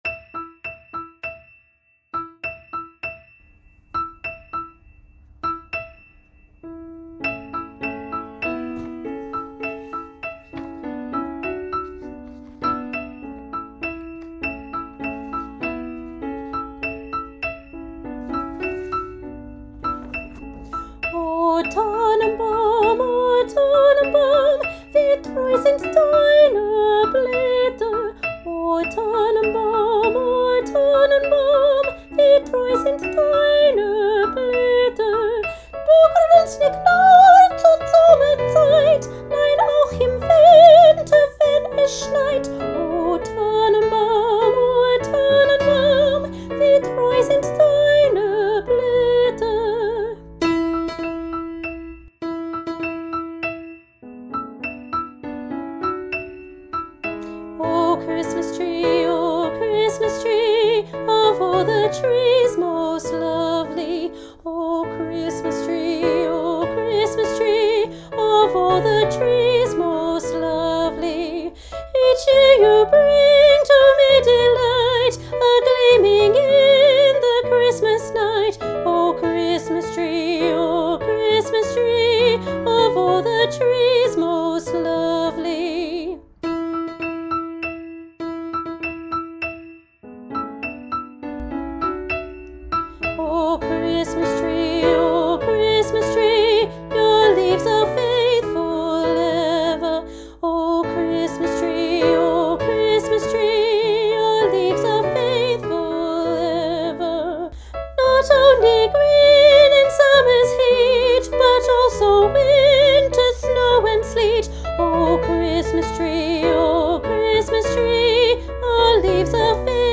Junior Choir – O Tannenbaum, Combined Piece, Middle Stave S2 – Alto